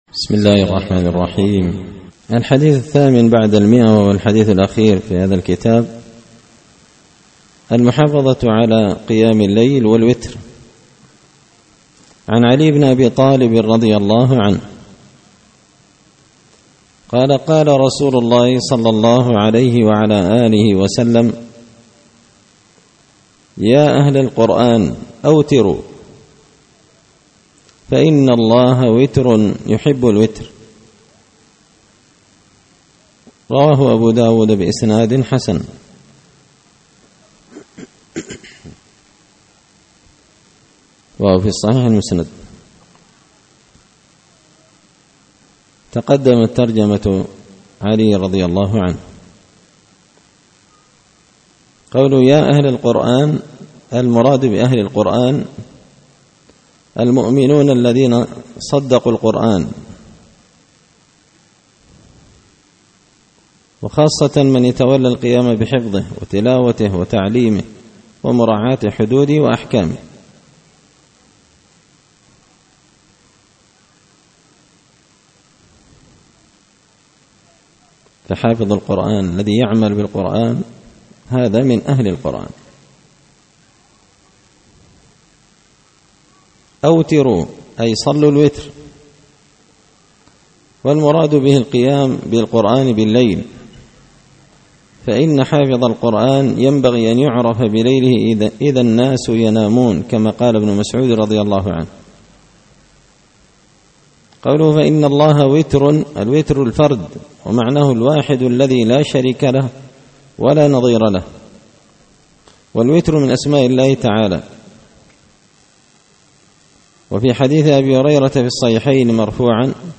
الدرس الرابع السبعون و الأخير
دار الحديث بمسجد الفرقان ـ قشن ـ المهرة ـ اليمن